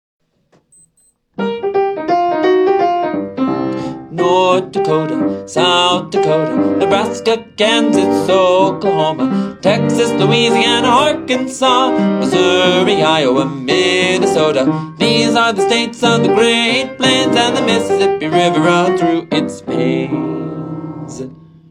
Easy Piano and Voice